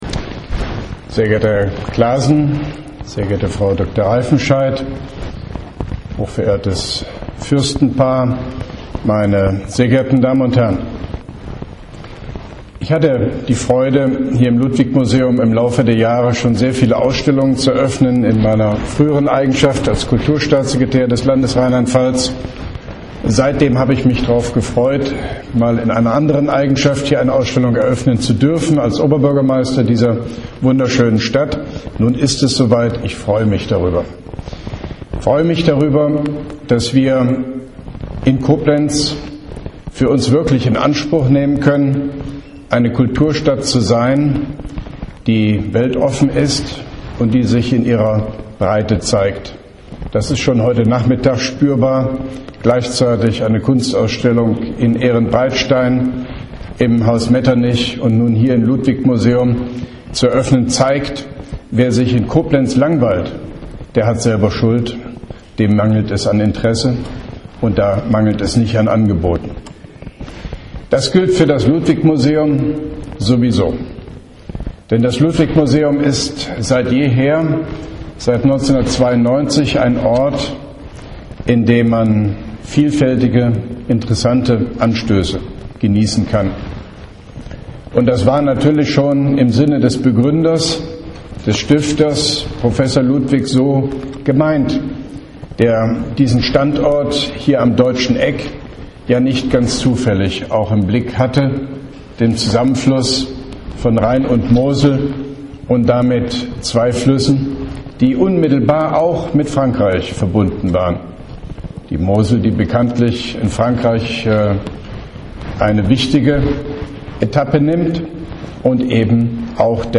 Mit einem Klick bitte die Audiodatei der Rede öffnen (Dauer 09:20 Minuten)